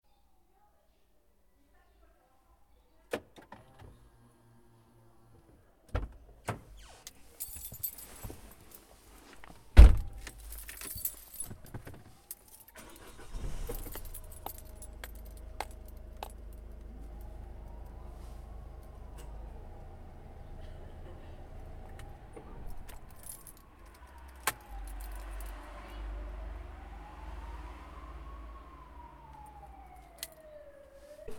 Entrada y arranque de un coche
Entrada y encendido de un vehículo diesel.